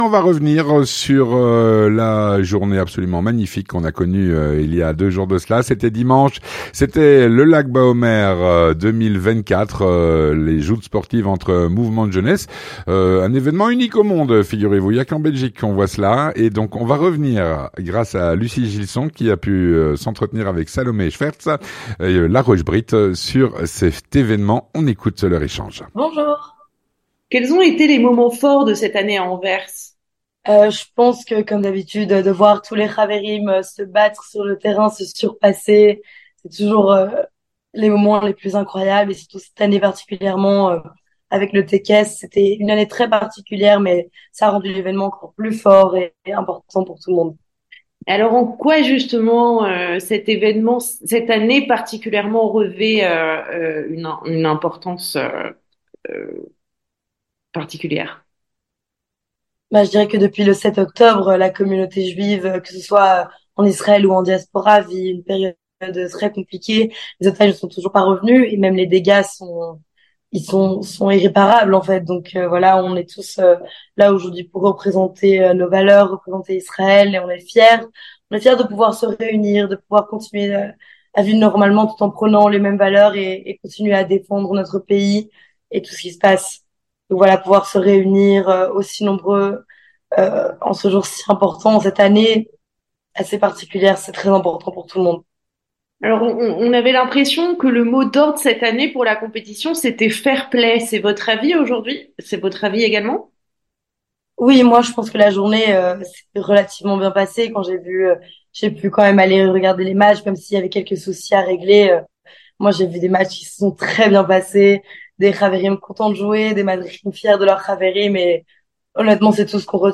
L'entretien du 18H - Retour sur la journée du Lag Baomer 2024.